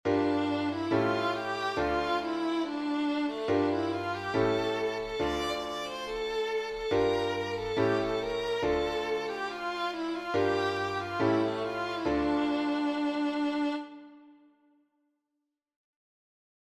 This is the refrain that comes from a Russian folk song.